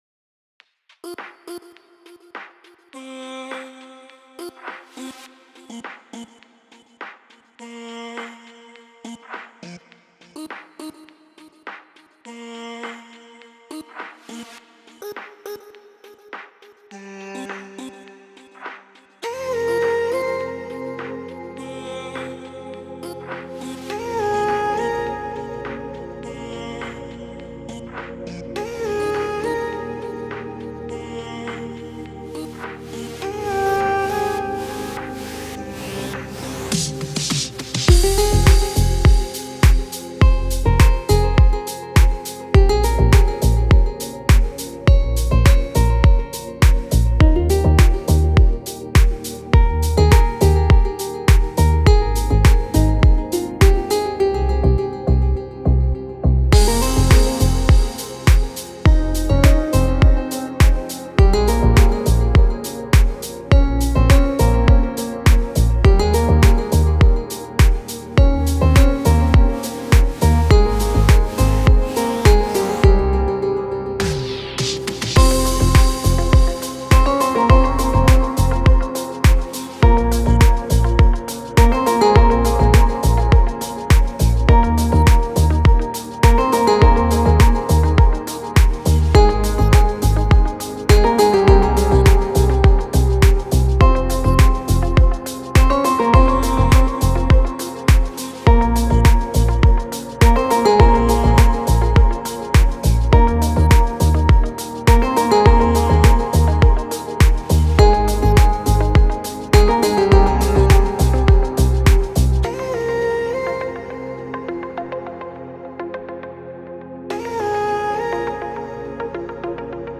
Категория: Спокойная музыка
Душевная музыка без слов
спокойная музыка